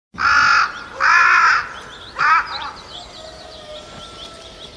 Turkish Crow And Rooster Botón de Sonido